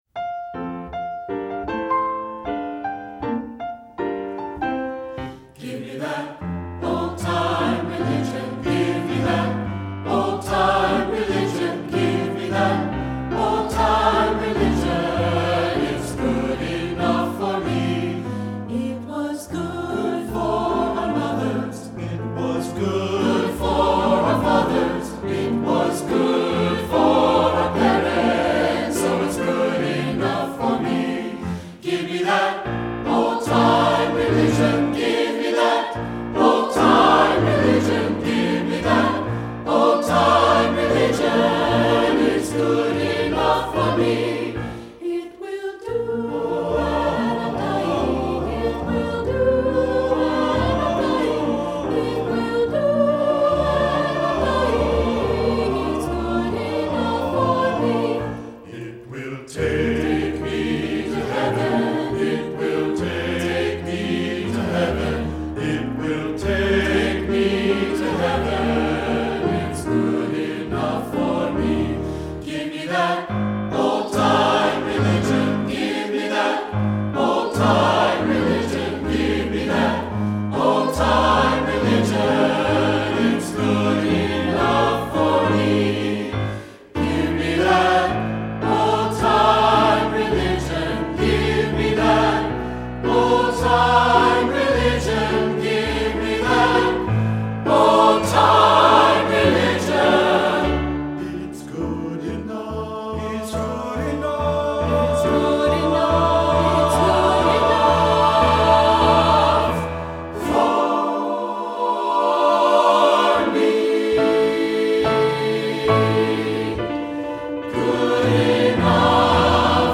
Composer: Traditional Spiritual
Voicing: SATB and Piano